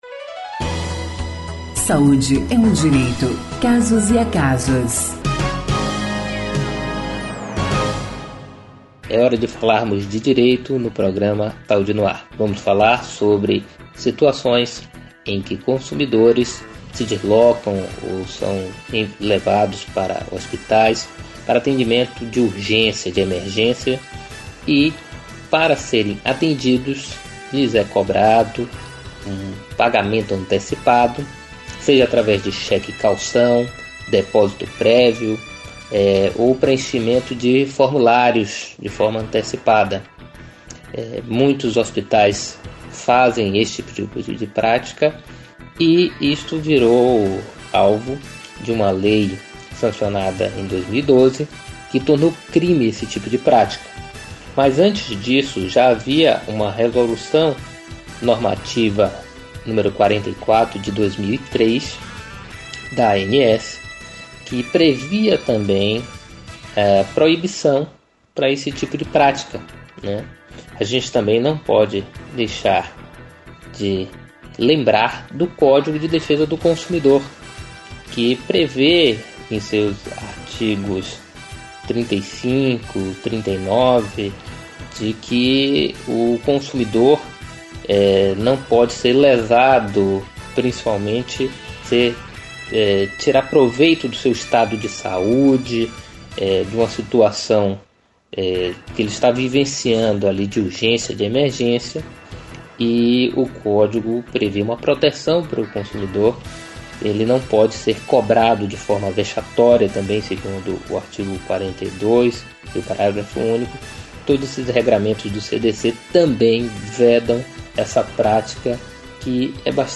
Quem fala mais sobre o assunto é o advogado
O quadro vai ao ar toda quarta-feira no Programa Saúde no ar exibido pela Rede Excelsior de Comunicação: Rádios Excelsior AM 840, FM 106.01, Recôncavo AM 1460 e pela Rádio web Saúde No Ar.